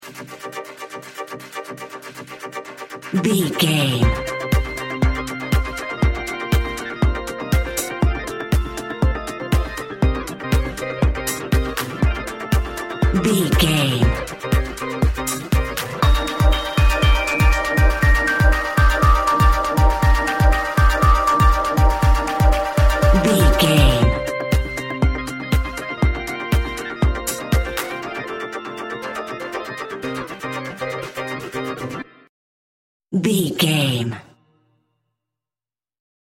Poweful Synth.
Aeolian/Minor
groovy
uplifting
lively
bouncy
playful
futuristic
drum machine
electric piano
synthesiser
electronic
techno
synth lead
synth bass
synth drums